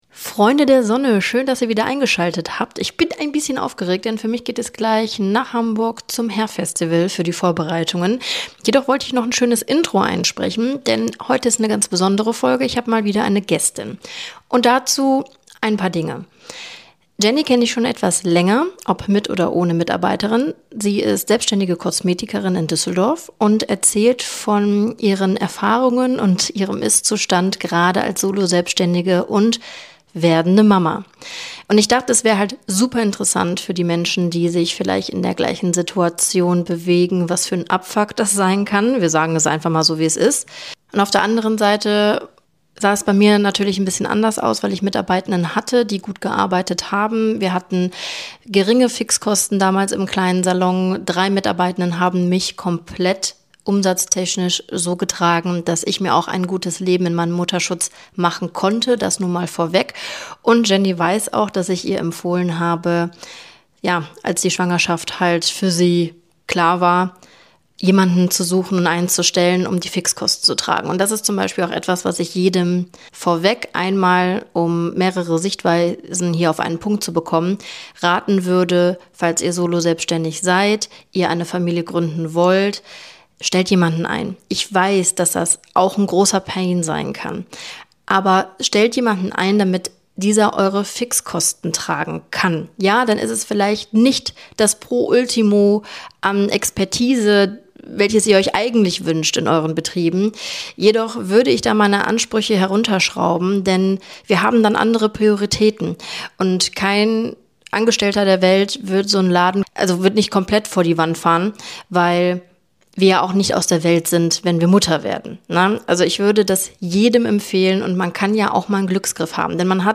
In dieser Folge spreche ich mit einer Kosmetikerin, die kurz vor der Geburt ihres Kindes steht und trotzdem Tag für Tag im eigenen Studio arbeitet.